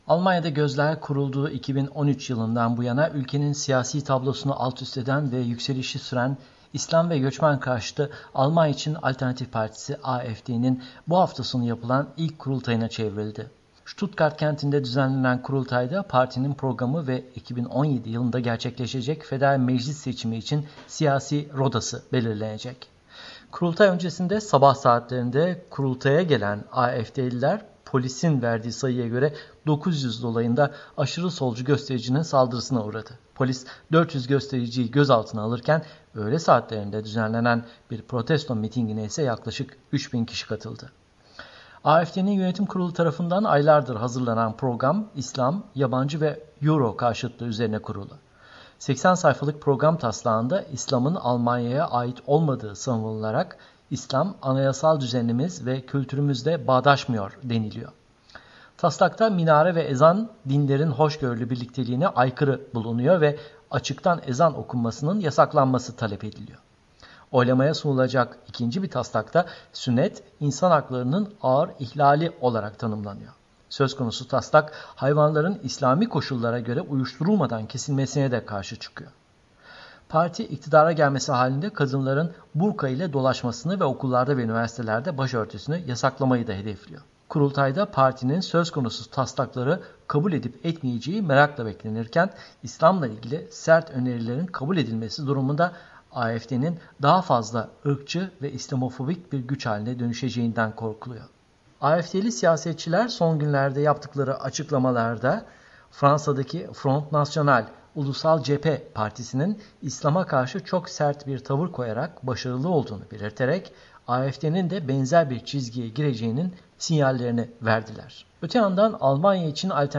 haberi